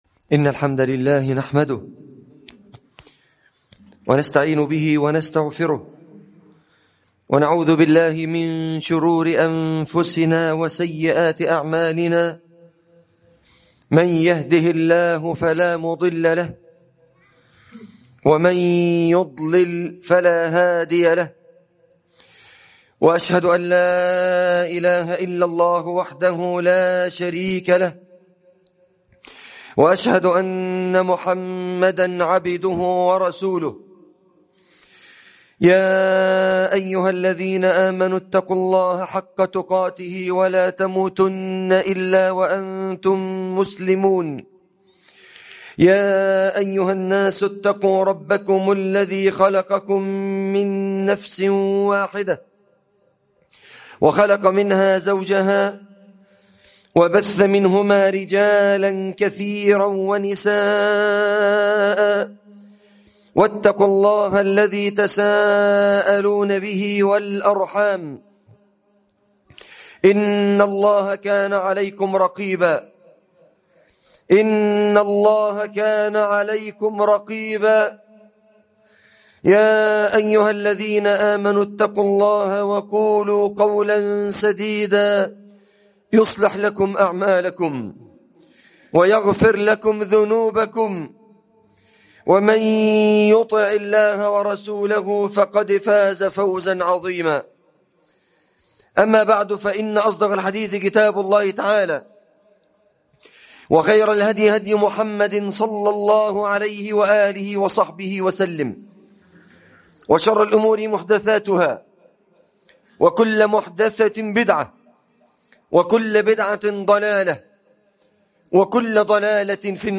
لهذا ثبتوا في غـزة | خطبة الجمعة